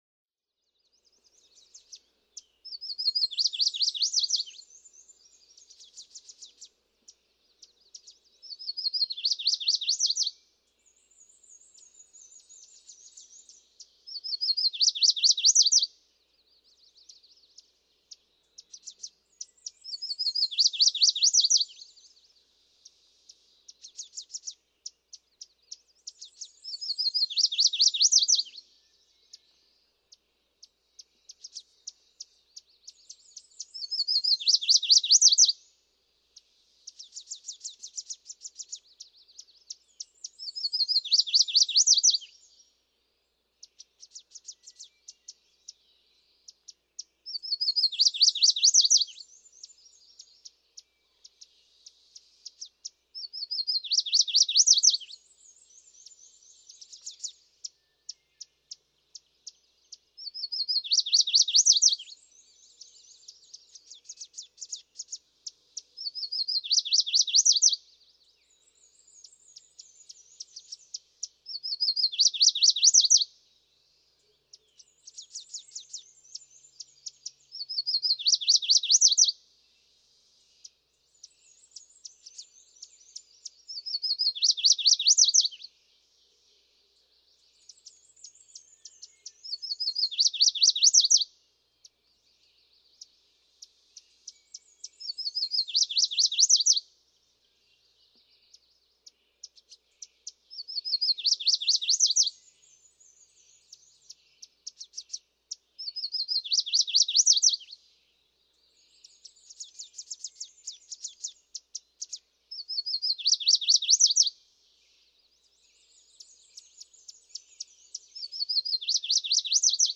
Chestnut-sided warbler
First of four neighboring birds with a unique dawn song, with chips between songs.
Savoy Mountain State Forest, Florida, Massachusetts.
567_Chestnut-sided_Warbler.mp3